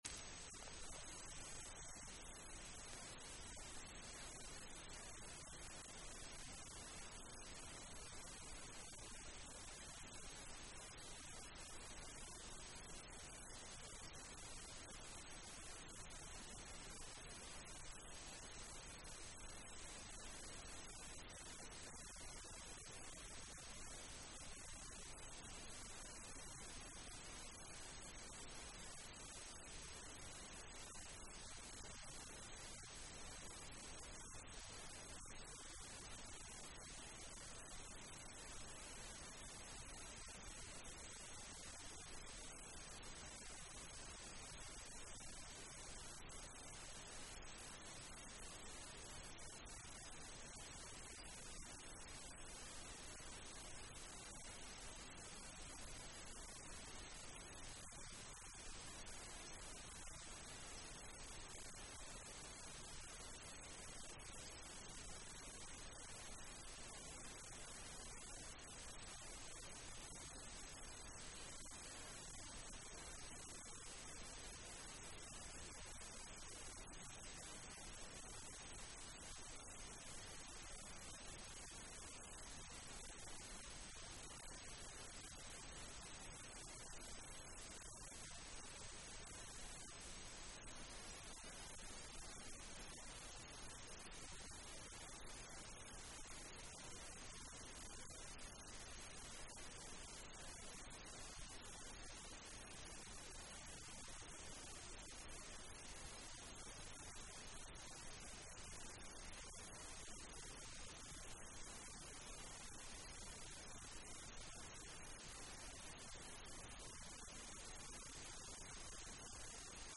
Enlace a Presentación del Proyecto de Digitalización del Area Comercial San Fernando